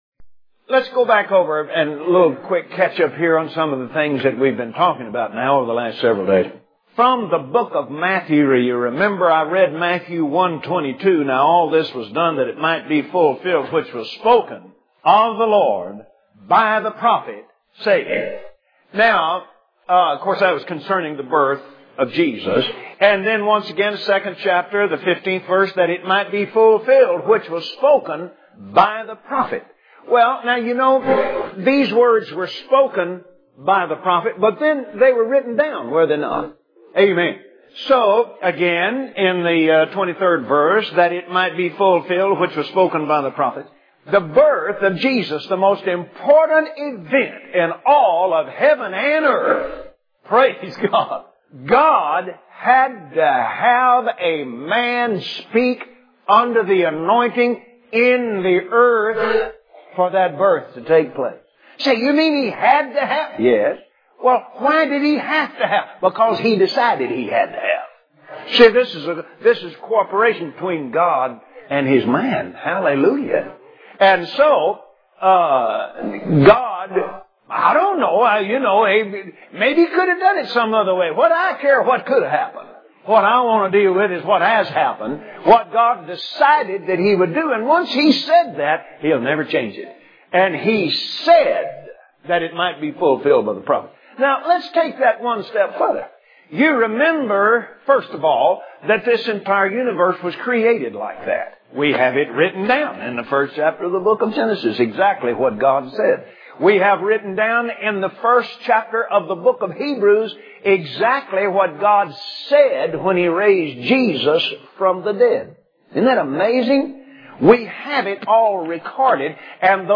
Index of /Audio/Sermons/Guest_Speakers/Kenneth_Copeland/YouAretheProphetofYourOwnLife/